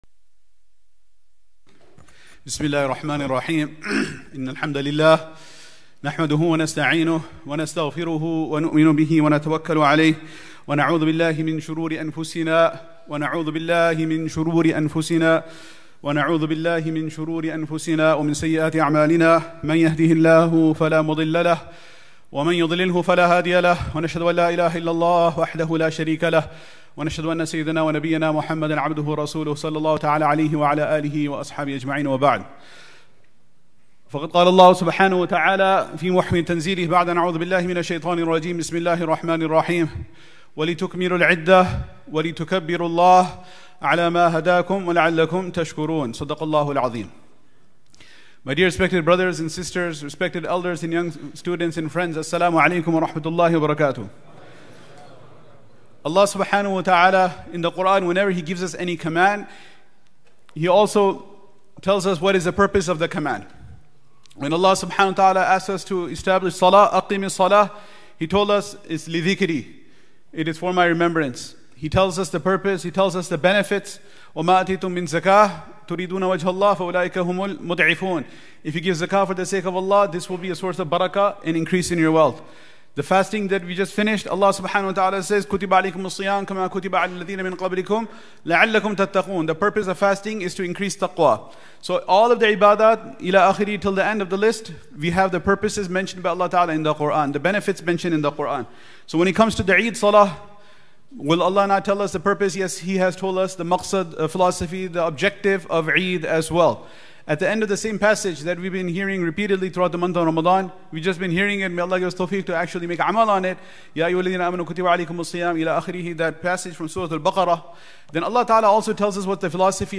Eid Khutbah I